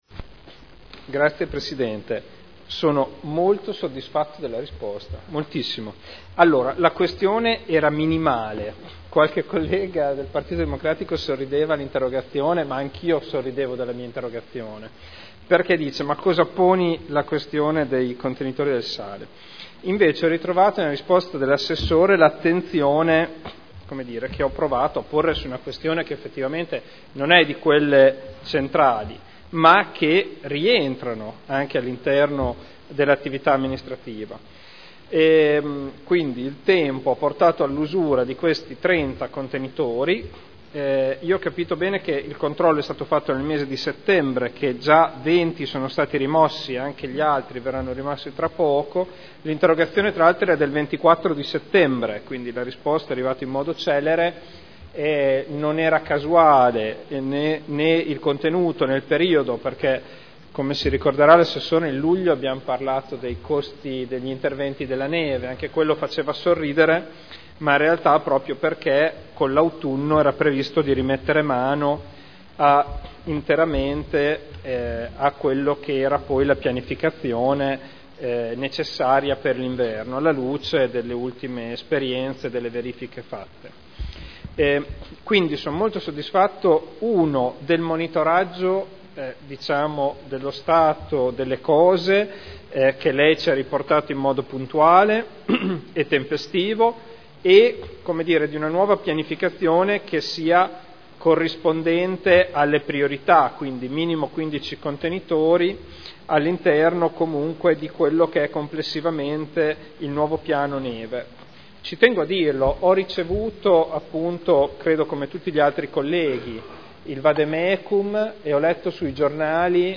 Seduta del 29/10/2012 Replica a risposta Assessore Marino su Interrogazione del consigliere Ricci (Sinistra per Modena) avente per oggetto: “Contenitori sale”